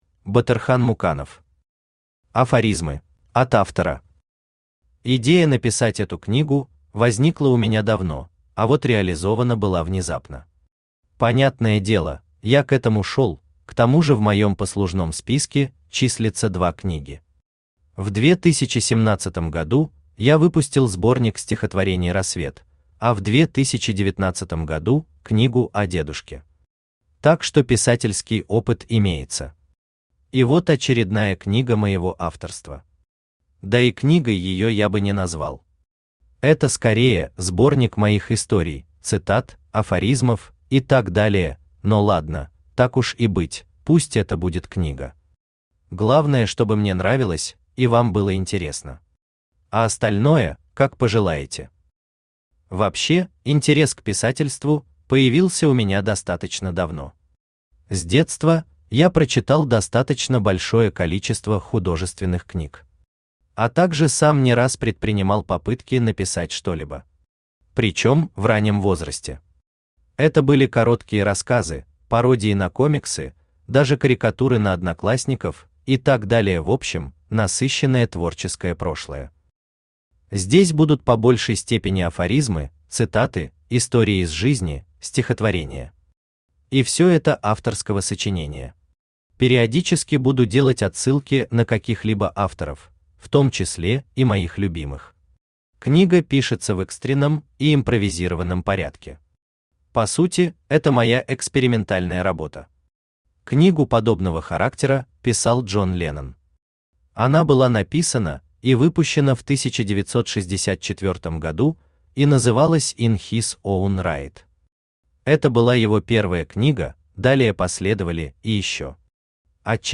Aудиокнига Афоризмы Автор Батырхан Муканов Читает аудиокнигу Авточтец ЛитРес.